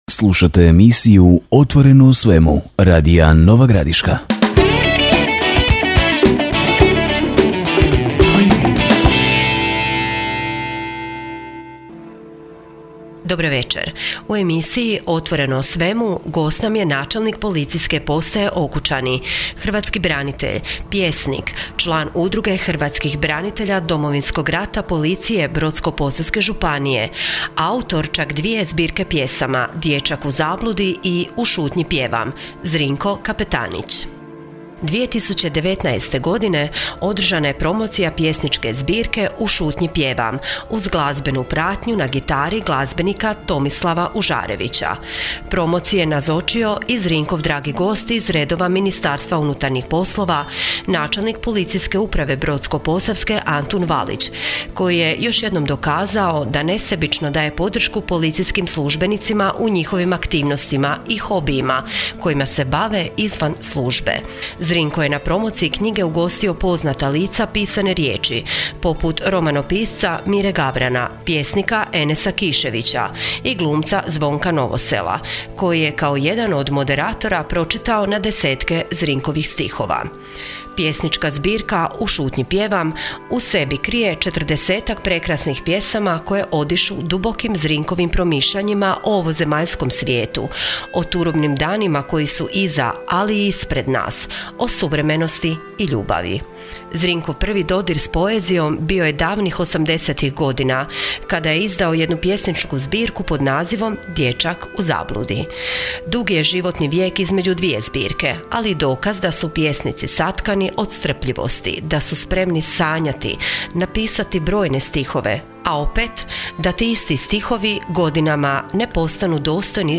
Radijska emisija: “OTVORENO O SVEMU”